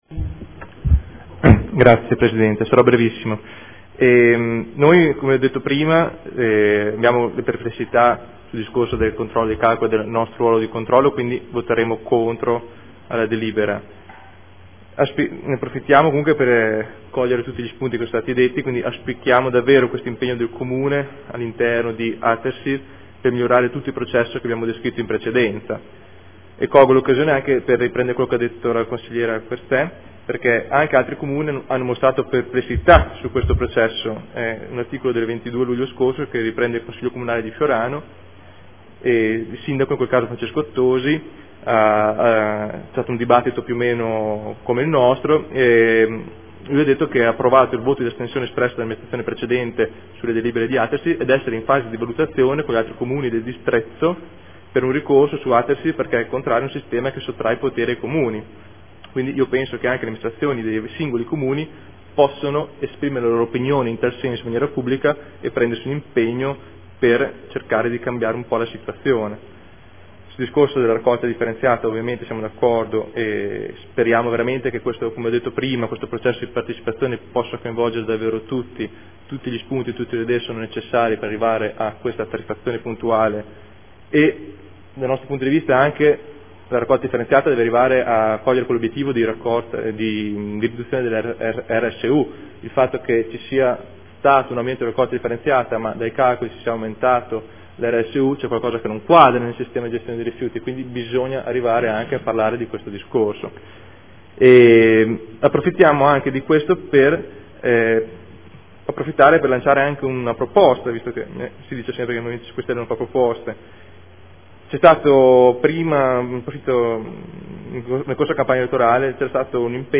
Marco Rabboni — Sito Audio Consiglio Comunale
Dichiarazione di Voto.